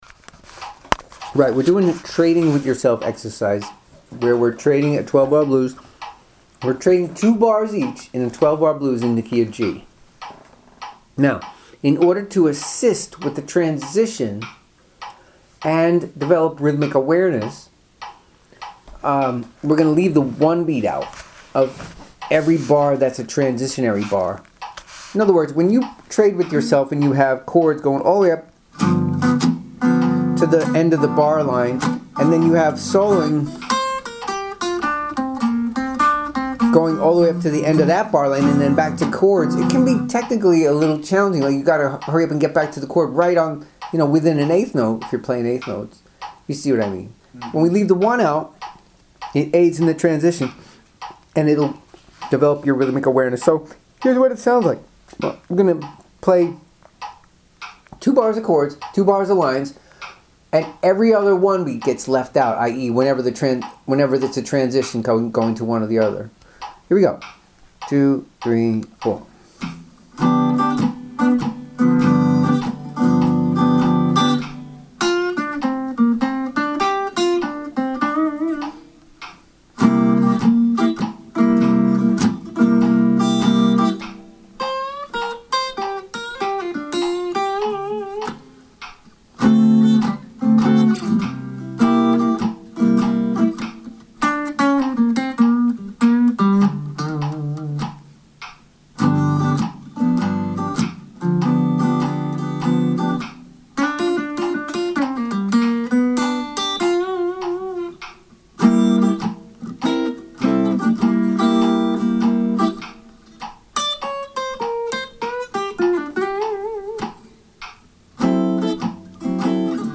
trade with yourself – exercises for solo blues playing